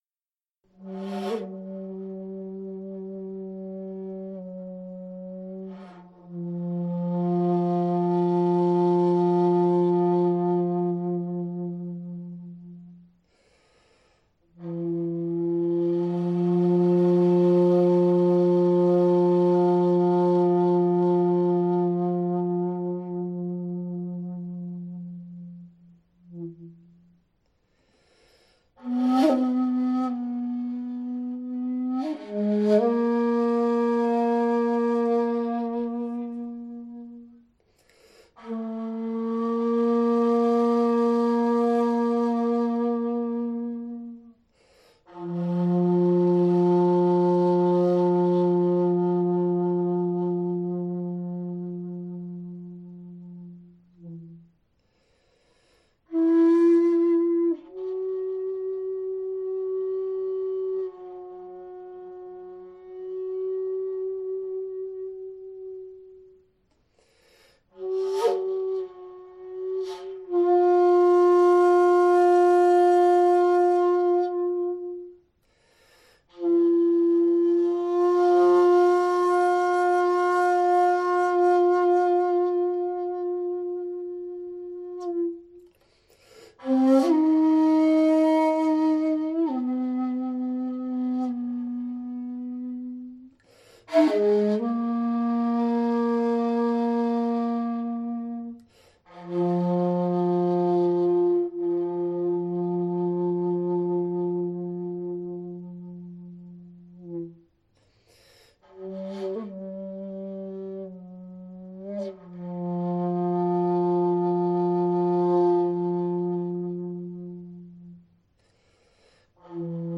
I have been entrusted a master shakuhachi to repair : a 3.0 ji-ari in 3 parts made by the famous Miura Ryuho.
As for the blowing, it is so surprising to feel the whole bamboo vibrating loudly with just a light stream of air… The tone colour is rich, and tuning and balance perfect up to dai-kan. So I tried, modestly and toughly, to play a Honkyoku